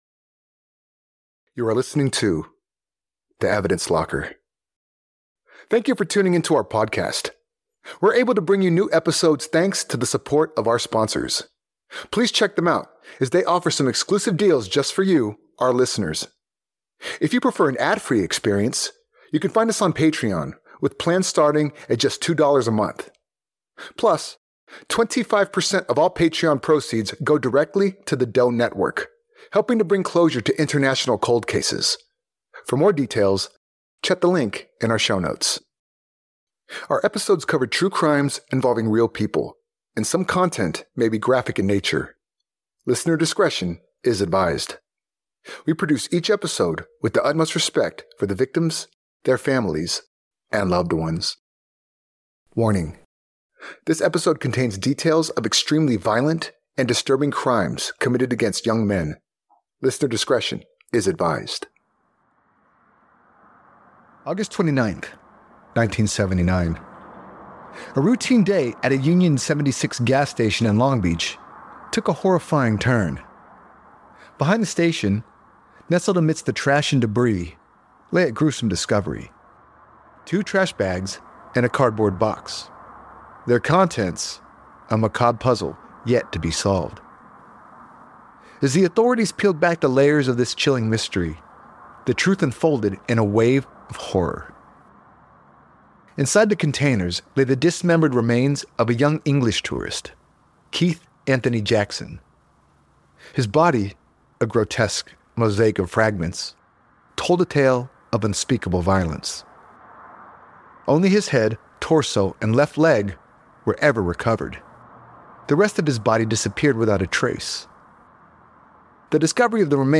Background track